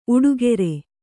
♪ uḍugere